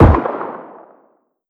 CookoffSounds / shotrocket / far_3.wav
far_3.wav